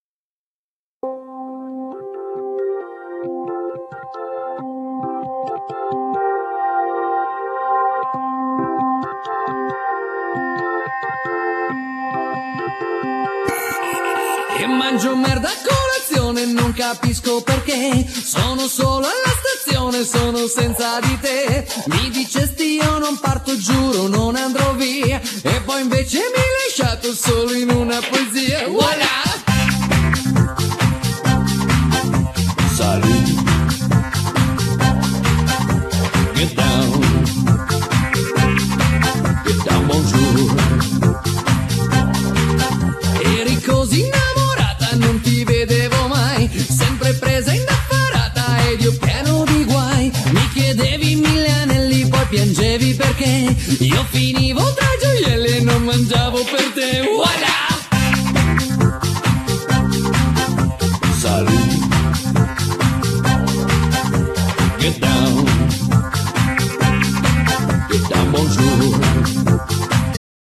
Genere : Pop / Rock